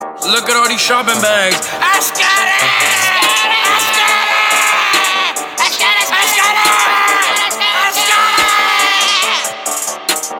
• Качество: 320, Stereo
мужской голос
громкие
крик